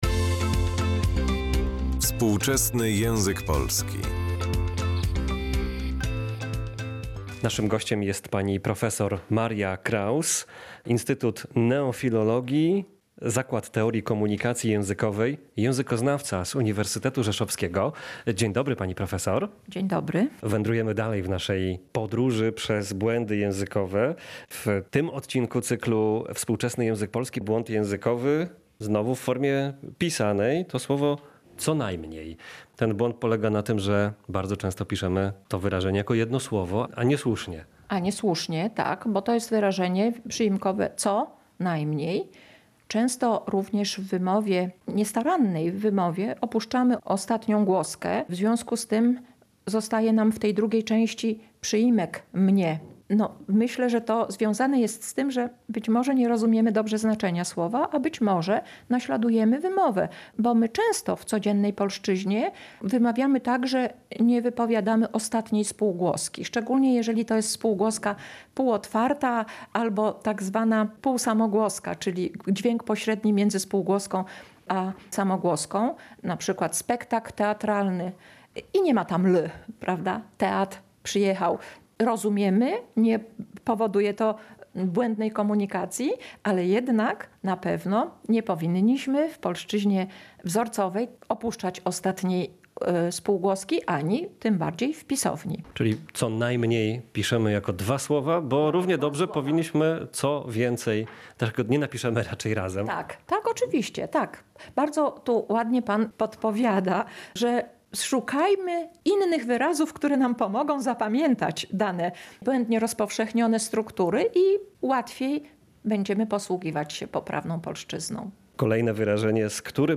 O tym rozmawiamy z ekspertem językowym w cyklu „Współczesny język polski”.
Rozmowa z językoznawcą